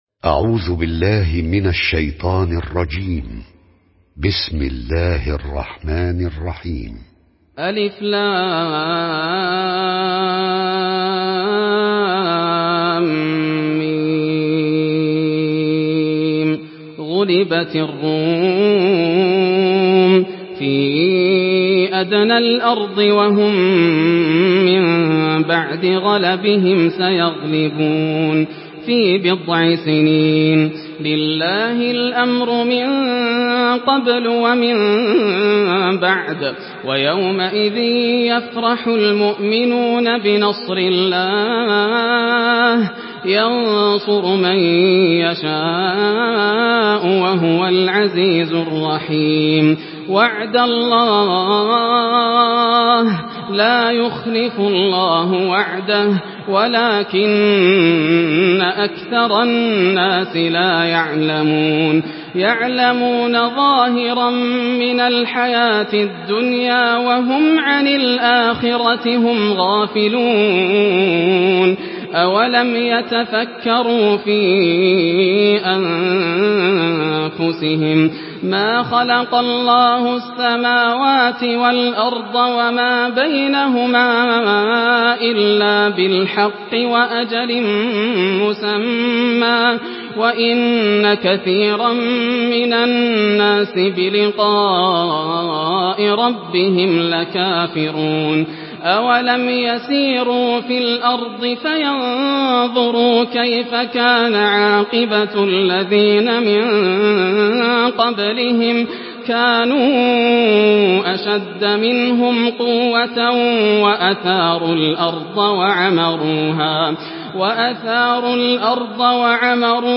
سورة الروم MP3 بصوت ياسر الدوسري برواية حفص
مرتل